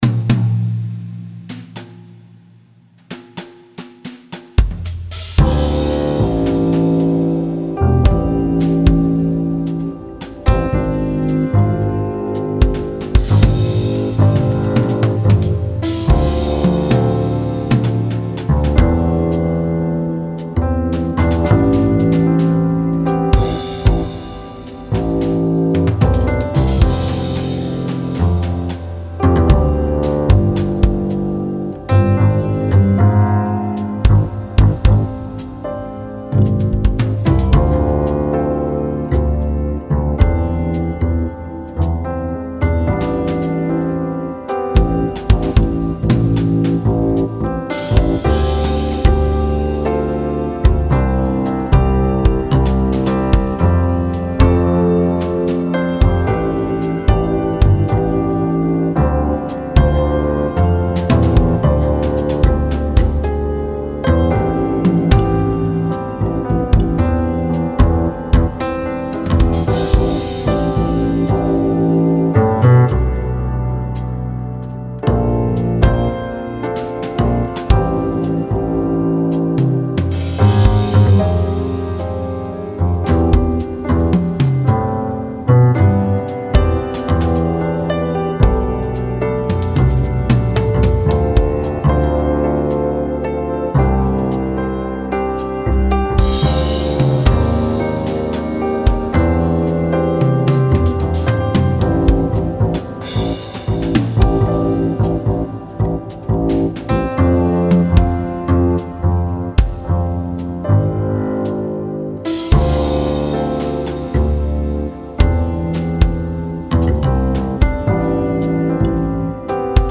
Komplett von mir erstellt ist der Upright-Bass.
Edit: Drums kommen vom SD3, hab den mit Jamstix angesteuert.